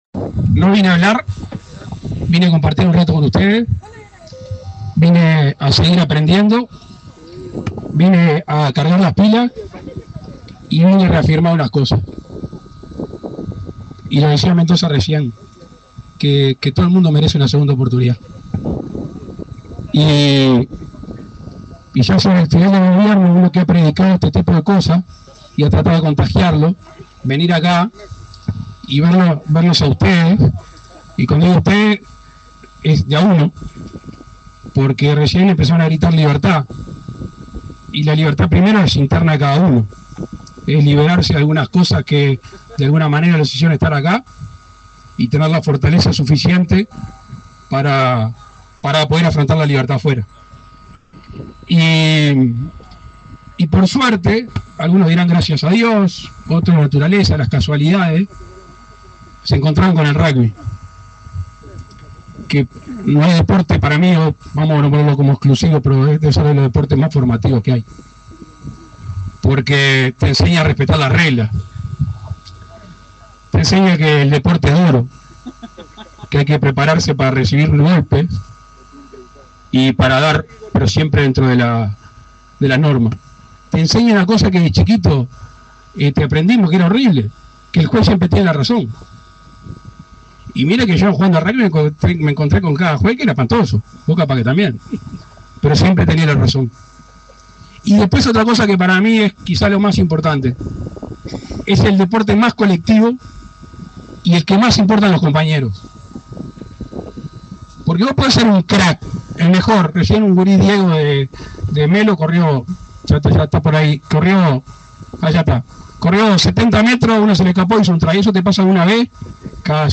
Palabras del presidente Luis Lacalle Pou
El presidente de la República, Luis Lacalle Pou, asistió al 2.° encuentro de Rugby Intercarcelario, en la Unidad n.° 1 de Punta de Rieles, en